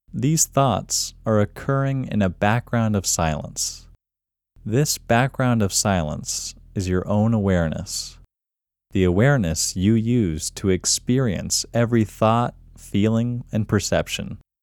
QUIETNESS Male English 5
The-Quietness-Technique-Male-English-5.mp3